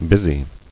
[bus·y]